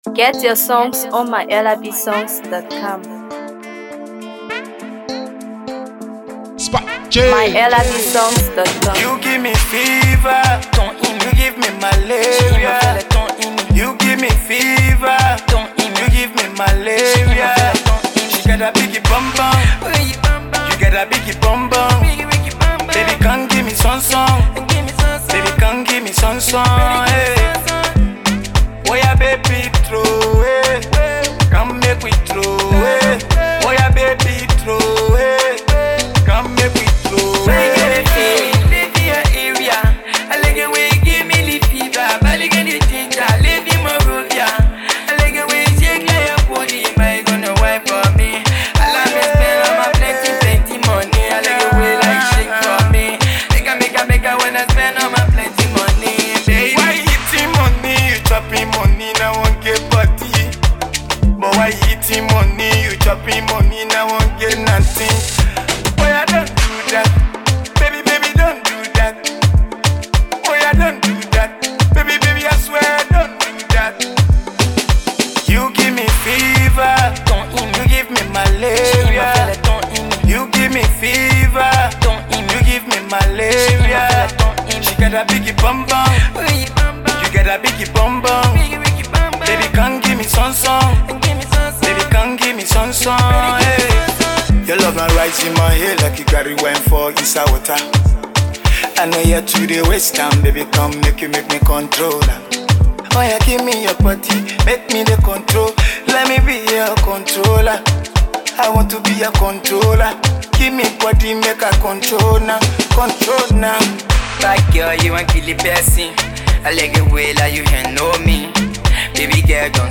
With its catchy hook and vibrant production